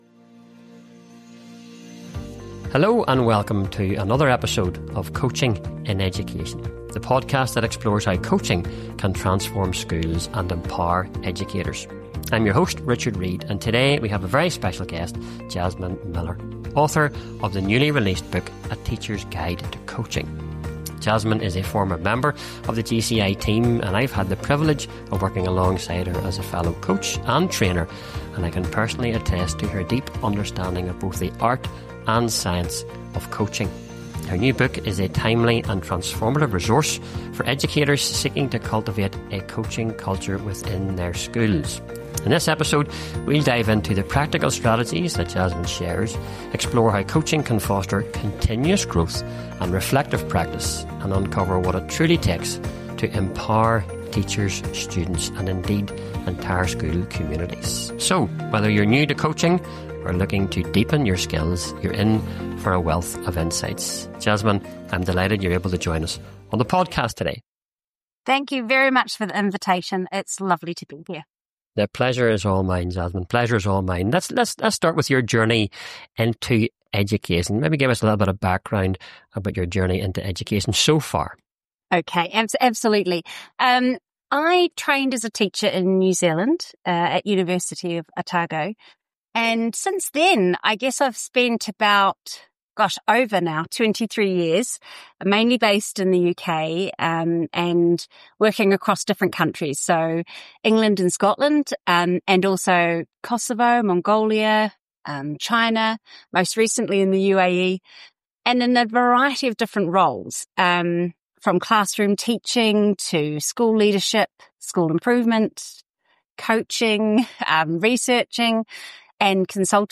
Whether you’re new to coaching or looking to deepen your skills, you won’t want to miss this conversation packed with practical strategies and real-life examples.